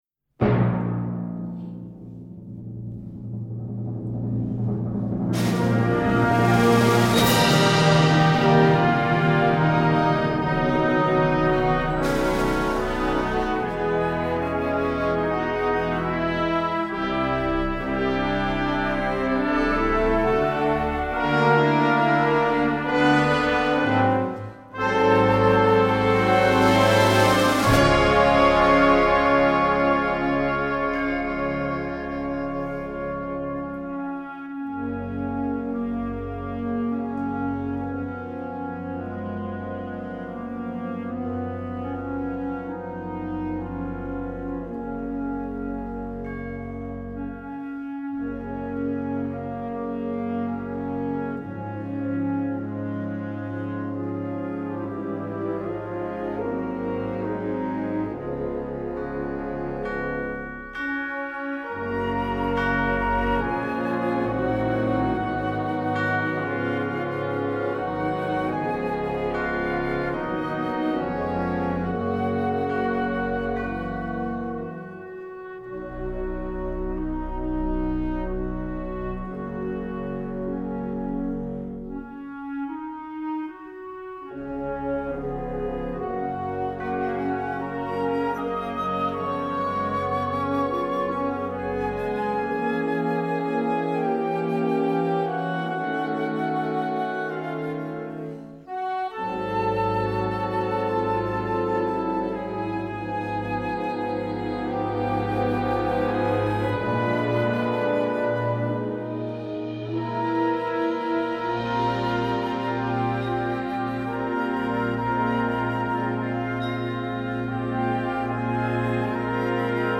Besetzung: Blasorchester
sumptuous feast of harmonic color and instrumental nuance